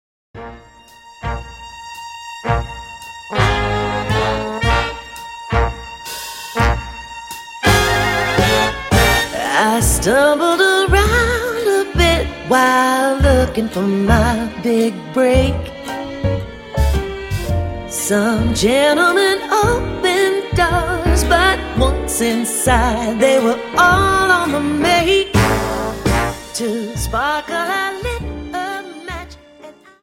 Dance: Slowfox 29 Song